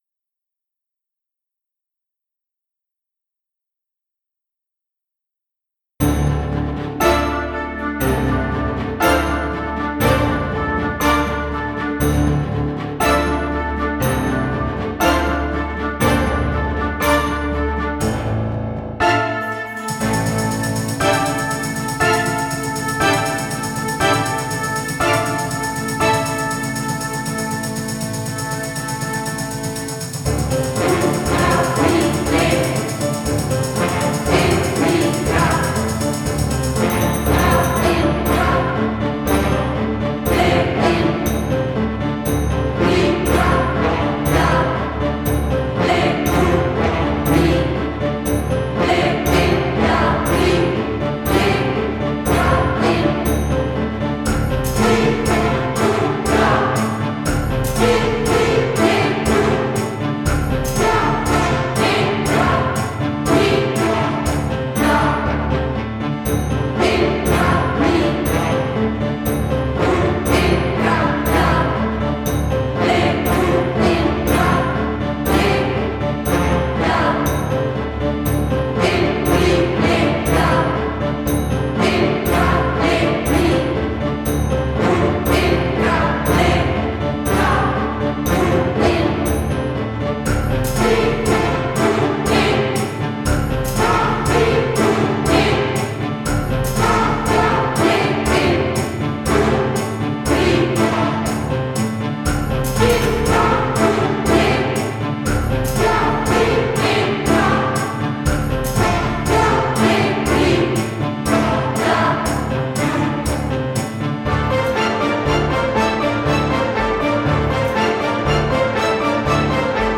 Ich habe drei Klangbeispiele aufgenommen, die alle aus dem klassischen Bereich stammen. Sie sind mit unterschiedlichen Instrumenten besett, um den jeweiligen Klangkörper hervor zu heben.
Die Instrumente, bis auf das Timpani, das etwas schwach auf der BRust ist, haben mich voll überzeugt.